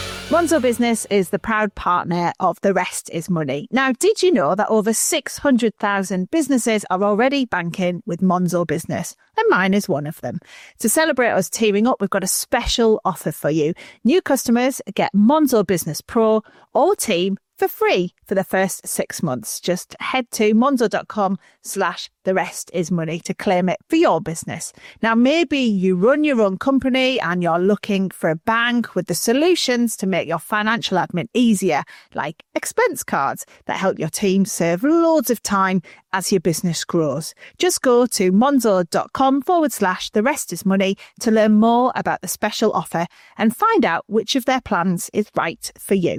Steph McGovern. Voice artist represented by United Voices.